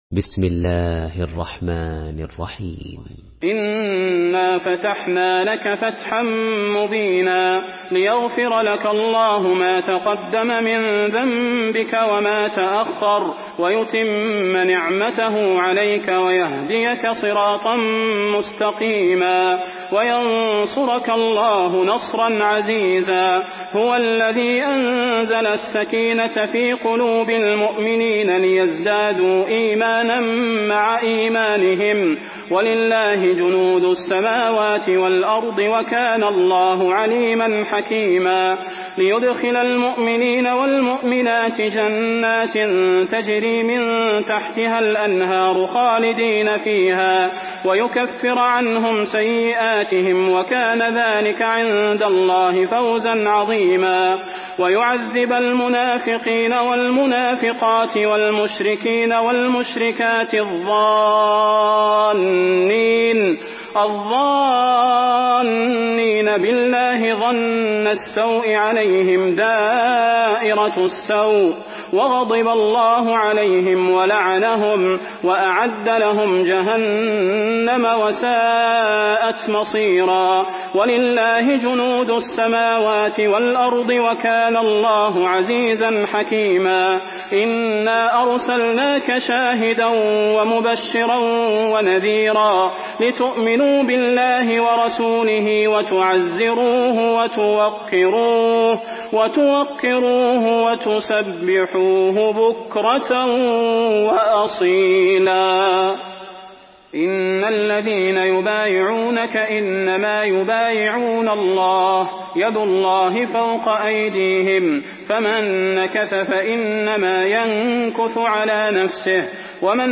Surah Al-Fath MP3 in the Voice of Salah Al Budair in Hafs Narration
Murattal Hafs An Asim